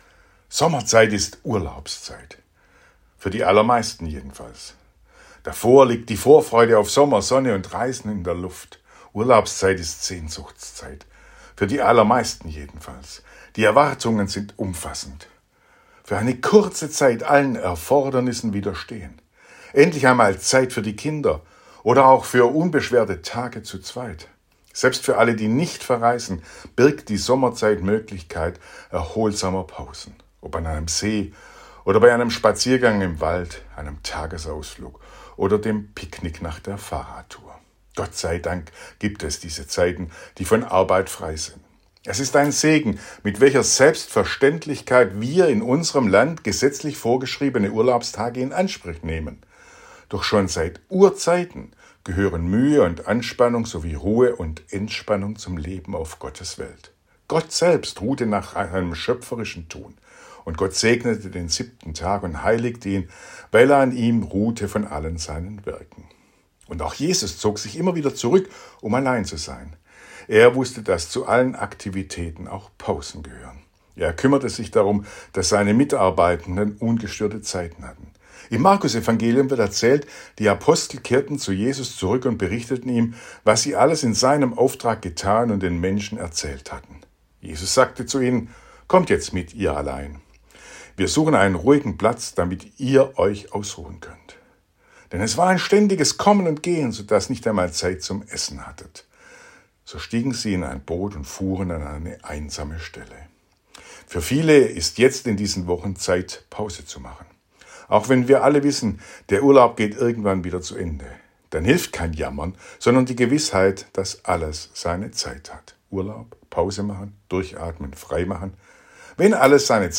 Radioandacht vom 5. August